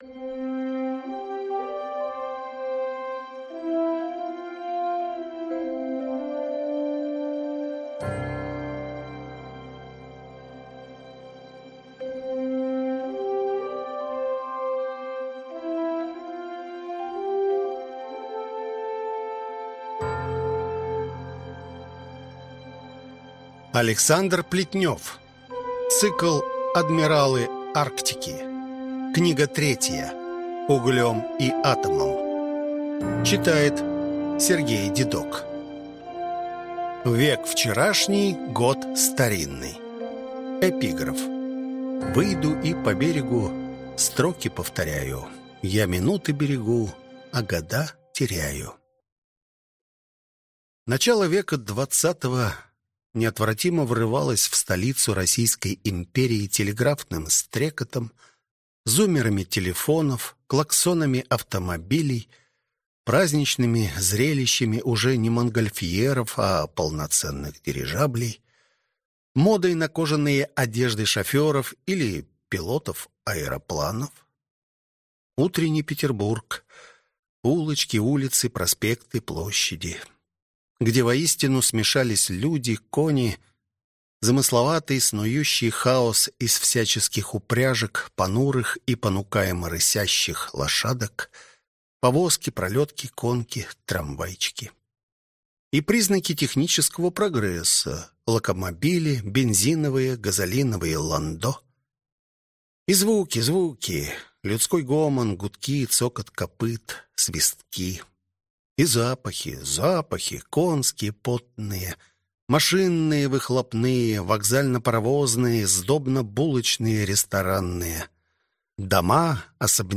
Аудиокнига Углём и атомом | Библиотека аудиокниг